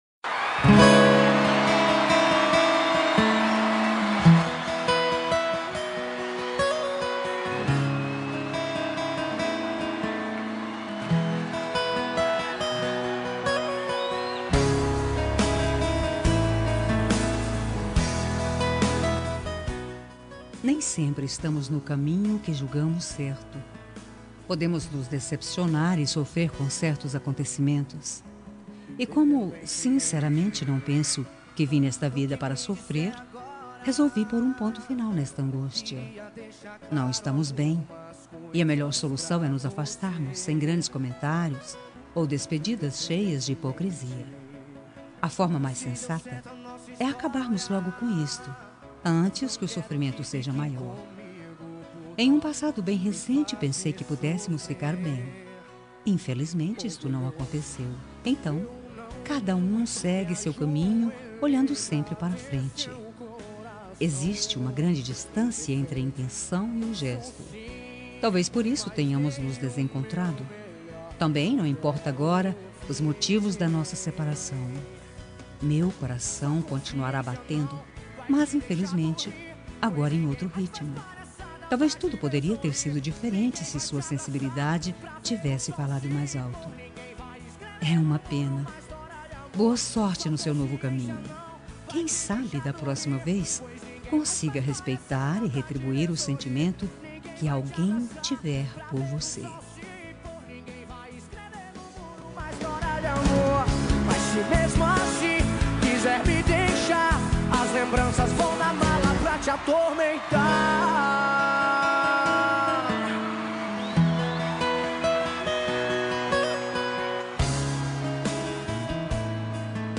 Término – Voz Feminina – Cód: 8663